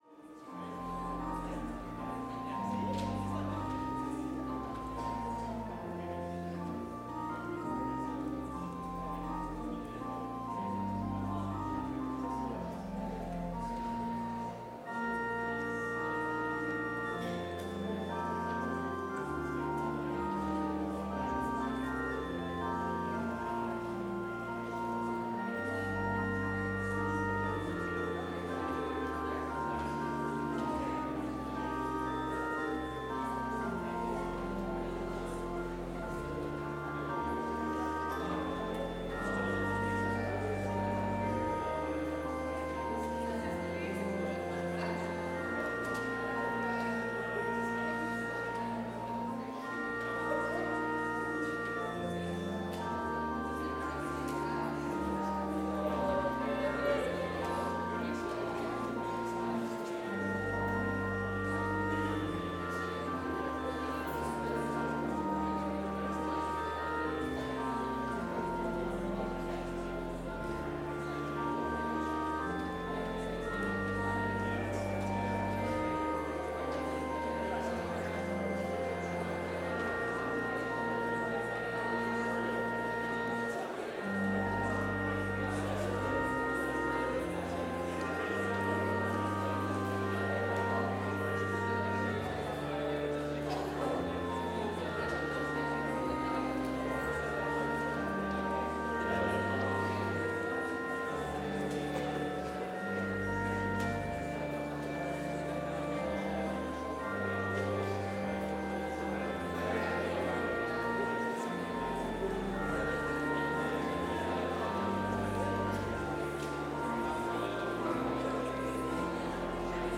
Complete service audio for Chapel - Wednesday, November 13, 2024